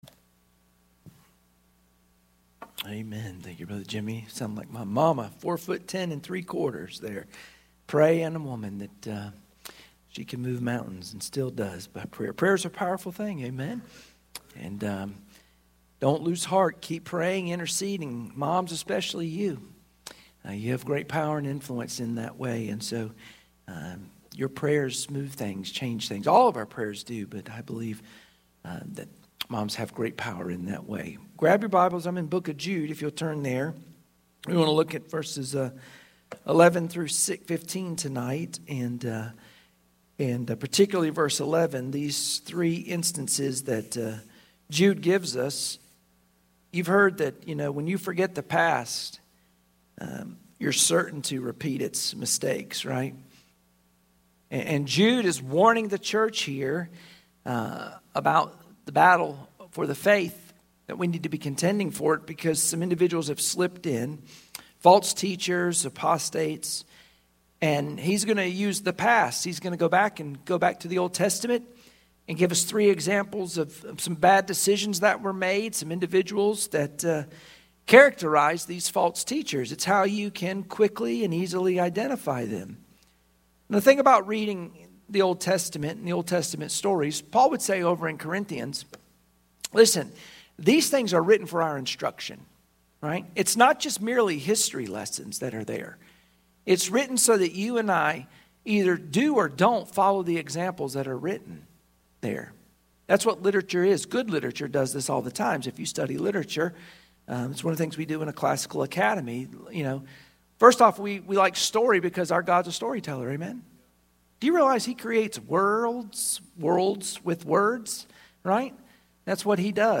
Sunday Evening Service Passage: Jude 11-16 Service Type: Sunday Evening Worship Share this